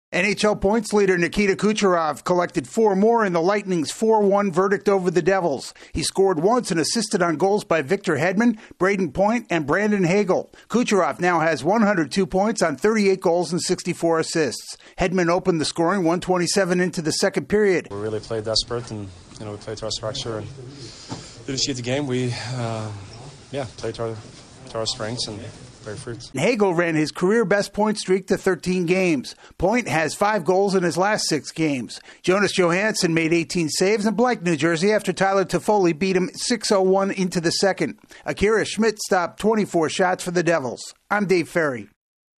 The Lightning complete a weekend sweep of the Manhattan suburbs. Correspondent